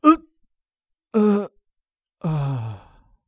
XS死亡1.wav
XS死亡1.wav 0:00.00 0:03.29 XS死亡1.wav WAV · 284 KB · 單聲道 (1ch) 下载文件 本站所有音效均采用 CC0 授权 ，可免费用于商业与个人项目，无需署名。